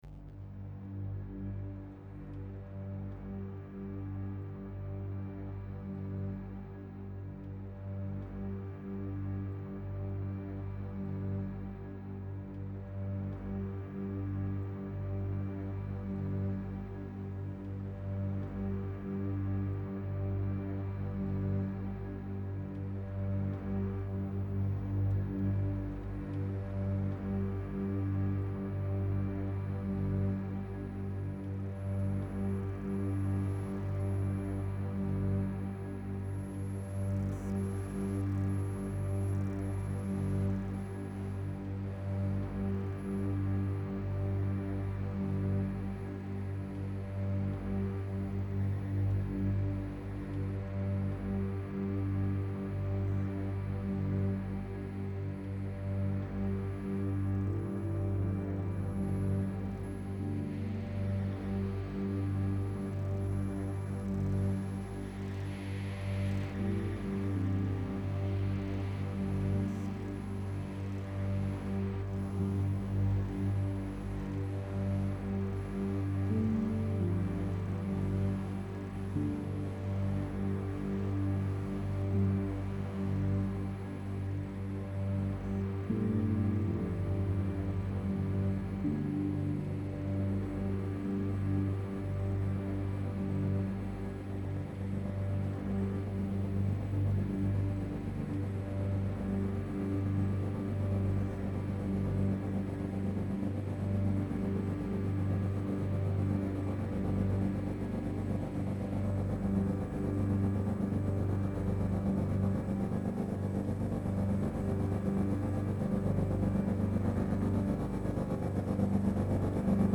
Soundscape for this site specific circus show.